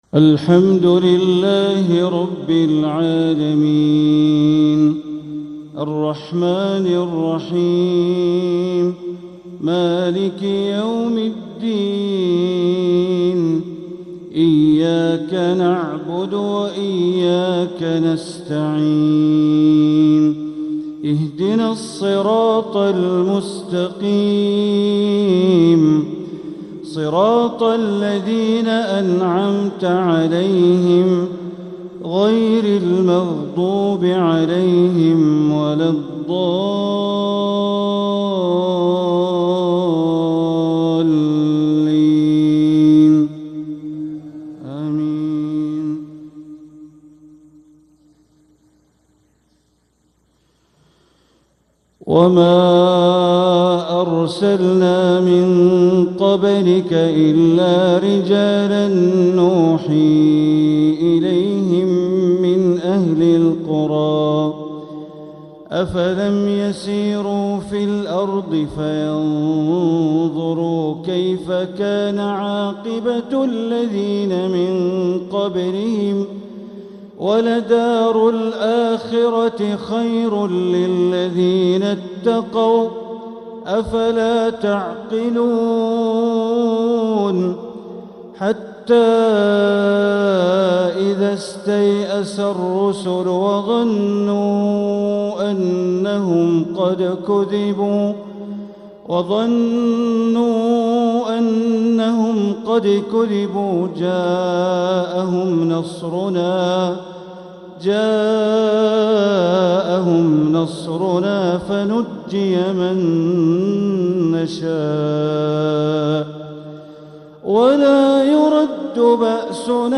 تلاوة من سورتي يوسف والنحل مغرب الثلاثاء ٢٧محرم ١٤٤٧ > 1447هـ > الفروض - تلاوات بندر بليلة